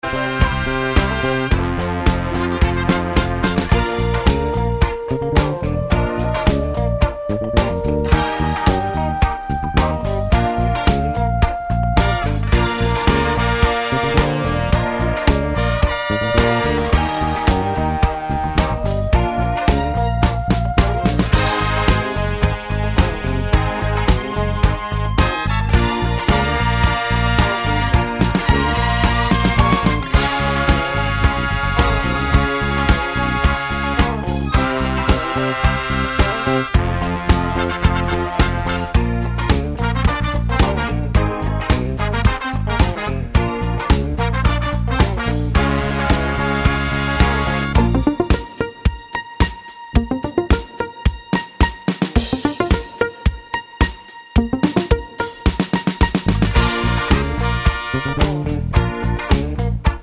no Backing Vocals Disco 3:08 Buy £1.50